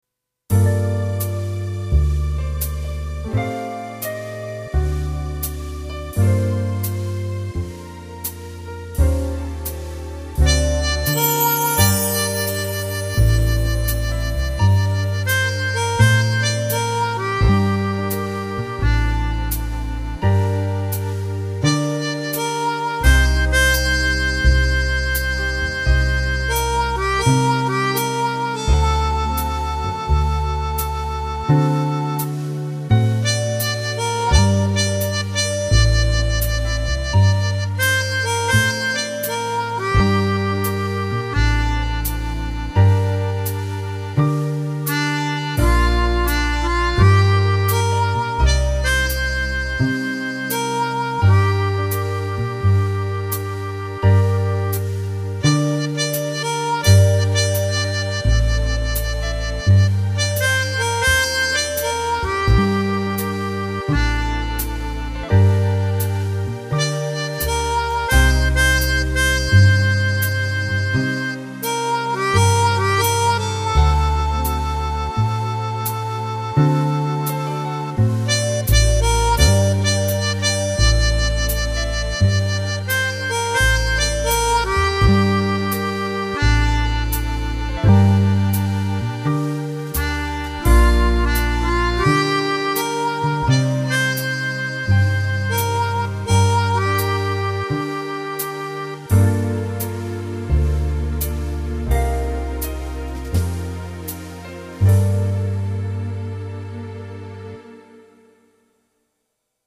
una ninna-nanna dolcissima
qui presentata in versione didattica per flauto.